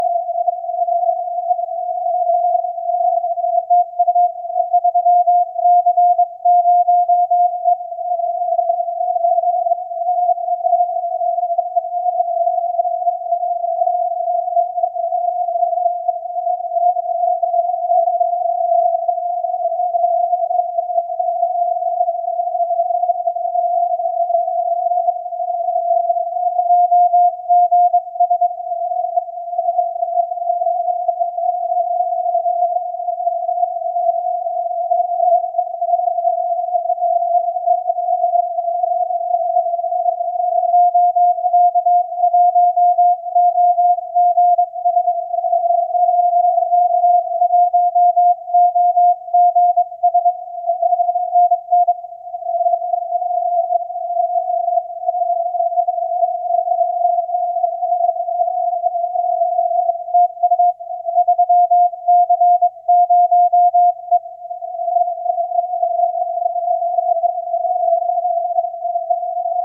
3CØE  160cw
et skimmer montre un mur assez dense de 10 Khz au dessus de 1825.
3CØE est S6 sur les pointes et S2 dans les creux du QSB.
le bruit est S1, quel rapport signal sur bruit !